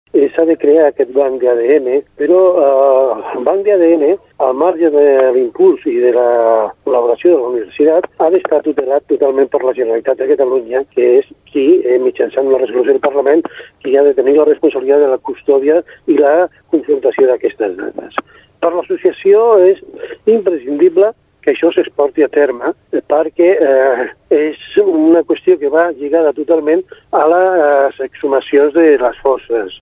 Carme García, directora general de Relacions Institucionals i amb el Parlament,  assegura que aquest banc es començarà a fer a partir d’ aquest setembre.